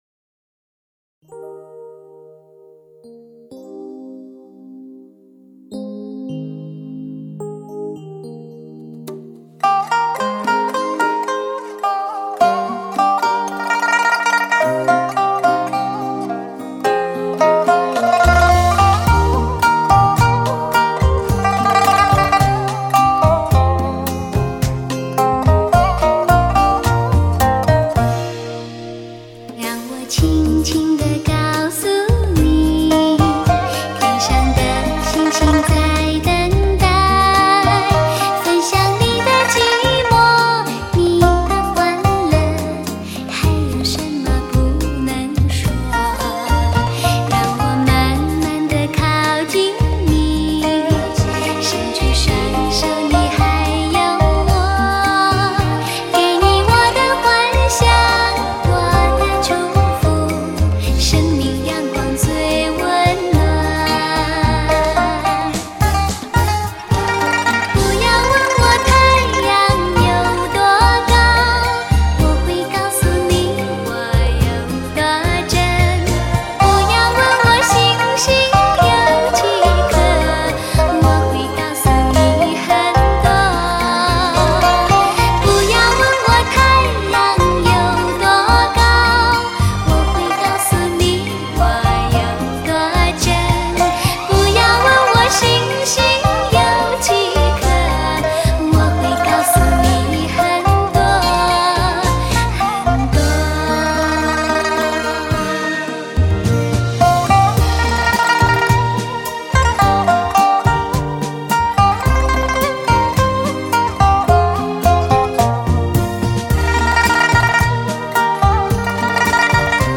按发烧级要求重新编配、真乐器伴奏